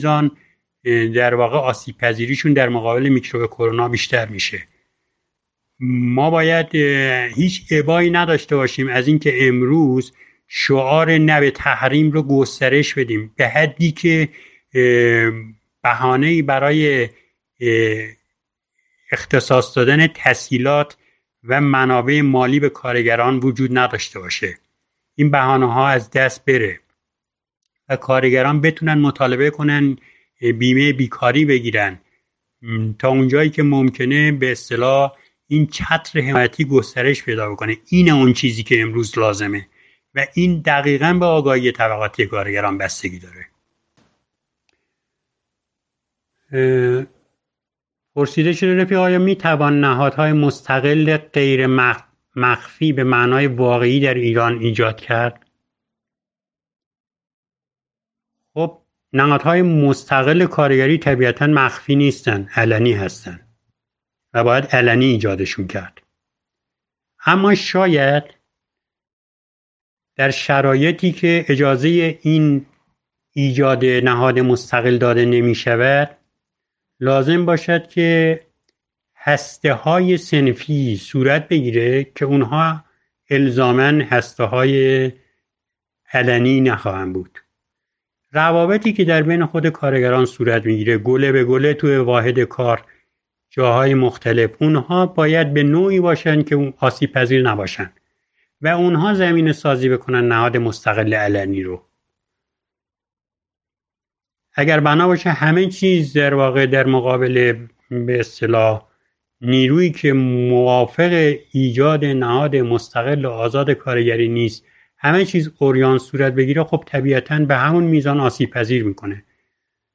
این جلسات معمولا ” هر جمعه ساعت ۹ شب به وقت ایران به طور موازی در بیش از ده گروه و کانال تلگرامی از جمله گروه ” به یاد رفیق رئیس دانا و برای اتحاد عدالتخواهان ” ، حمایت از زندانیان سیاسی و عقیدتی و کانال تریبون سرزمین من (اجتماعی،سیاسی،فرهنگی) برگزار میشود.